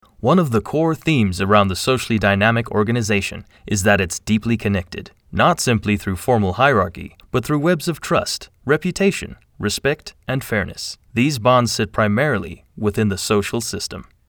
企业宣传片